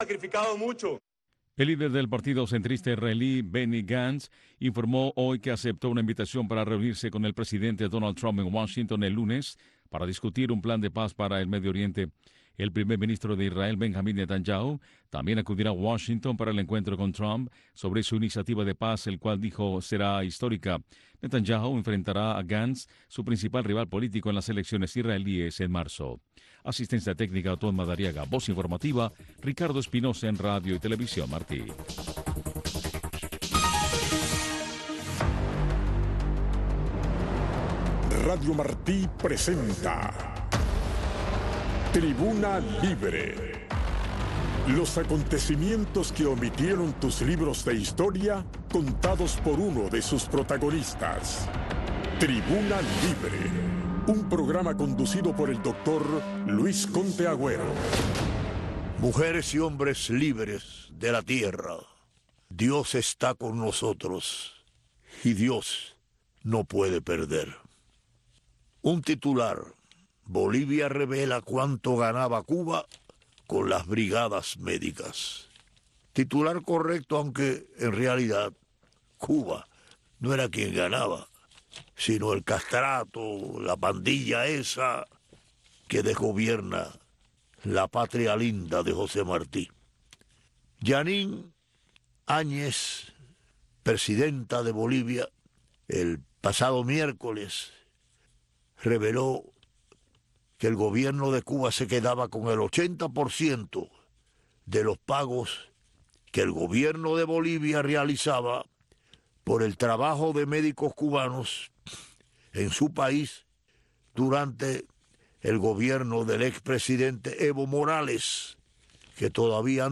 Radio Marti presenta Tribuna Libre. Los acontecimientos que omitieron tus libros de historia, contados por uno de sus protagonistas. Un programa conducido por el Doctor: Luis Conté Agüero.